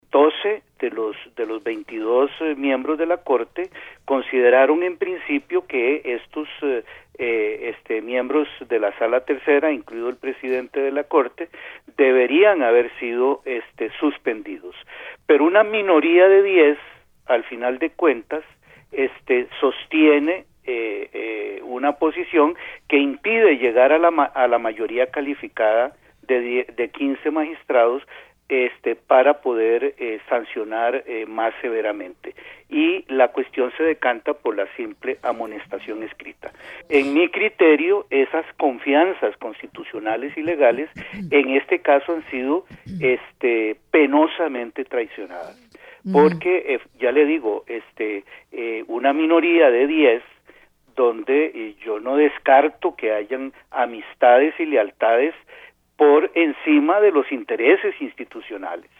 El exmagistrado y exvicepresidente de la Corte Suprema de Justicia, José Manuel Arroyo, afirmó en «Noticias CRC 89.1 HOY», que no descarta que haya amistades dentro de la misma Corte para haber tomado esa decisión.
AUDIO-EX-MAGISTRADO.mp3